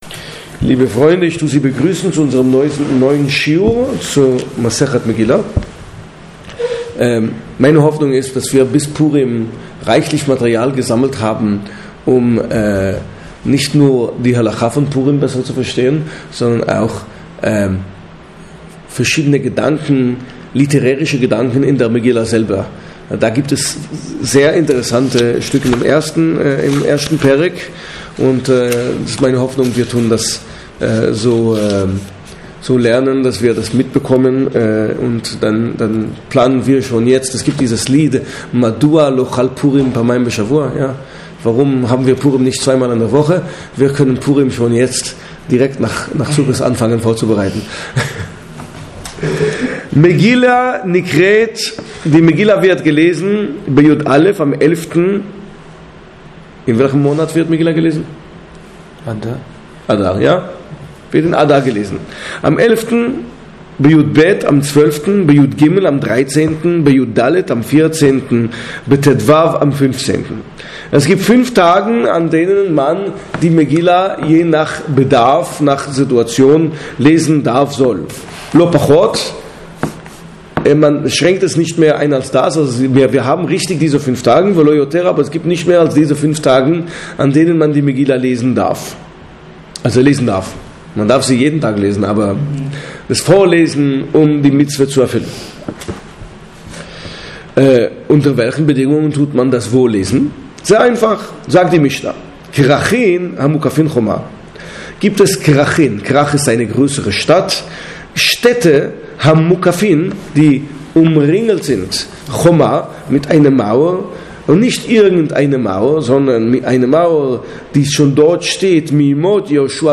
Der hebräische Text, der in diesem Vortrag gelesen und kommentiert wird ist Megillá Folio 2a.